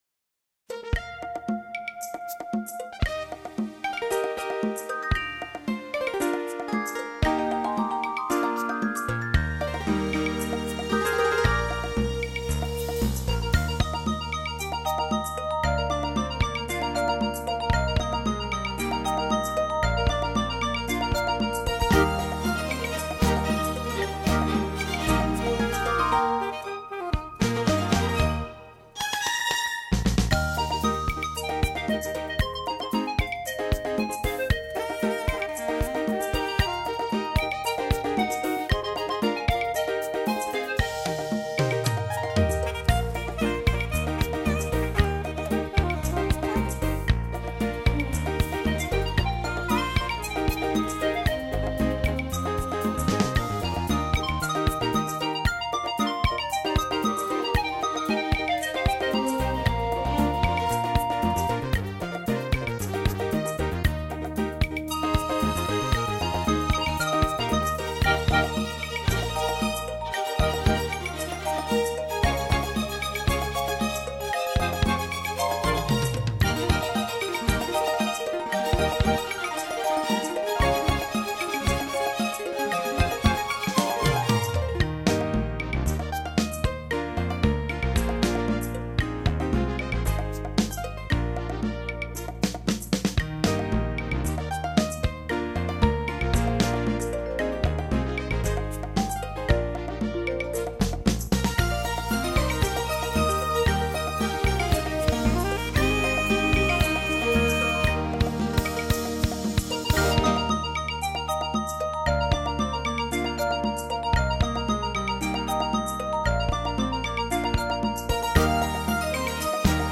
合成器、鼓、低音吉他、萨克管、小号、小提琴、大提琴、琵琶、柳琴、擂琴、高胡、二胡、中胡、合唱